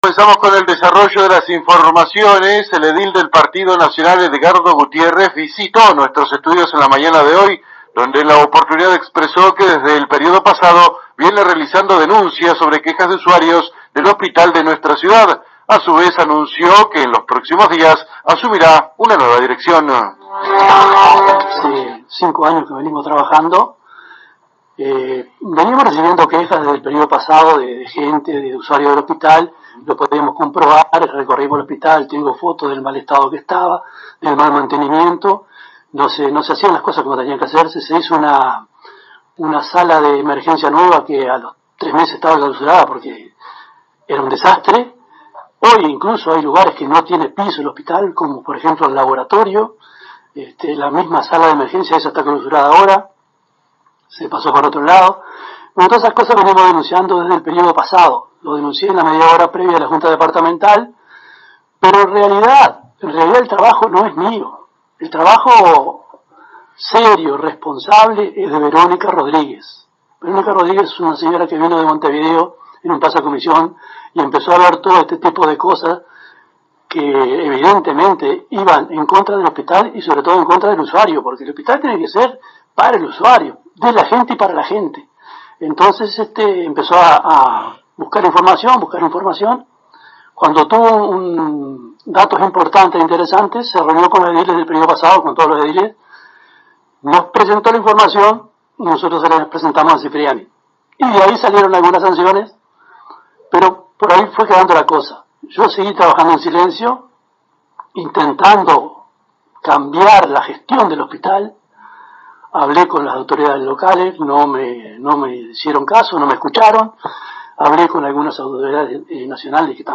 El edil isabelino Edgardo Gutiérrez visitó esta mañana los estudios de la AM 1110 local para informar los próximos cambios que vienen para el Hospital de Paso de los Toros.